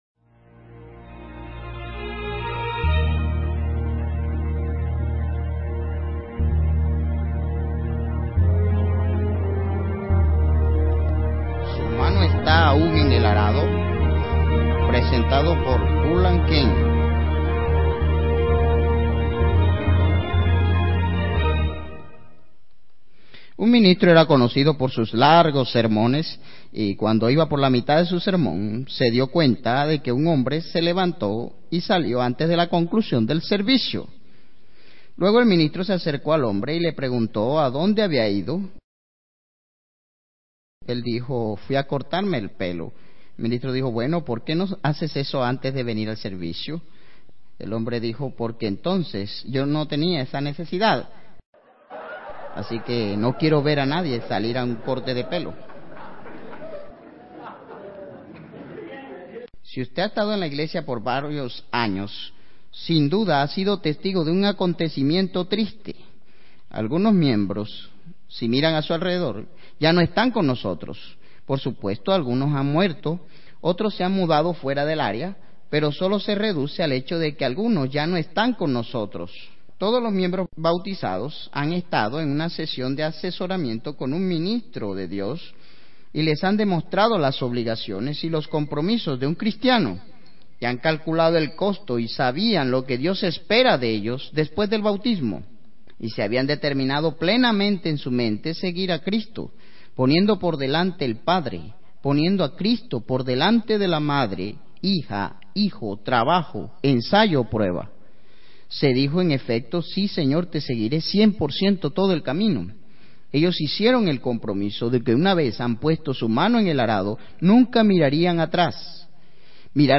Sermones
Given in Ciudad de México